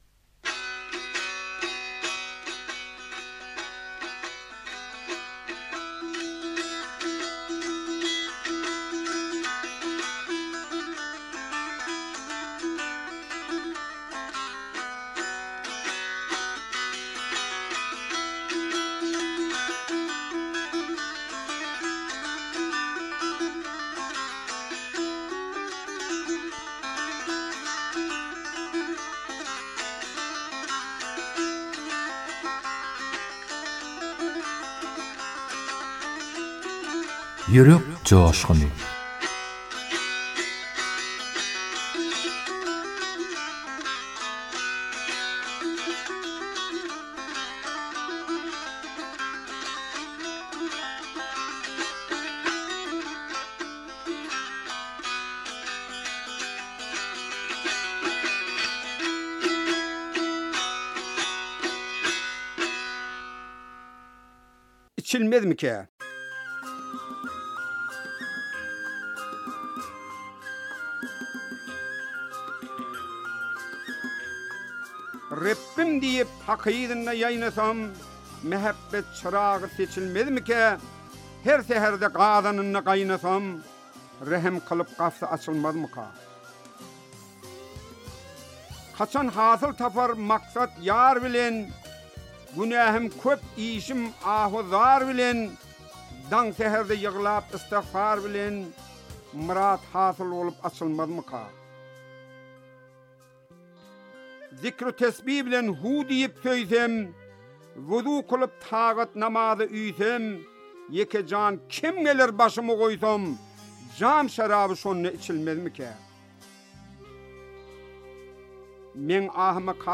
owaz aýdym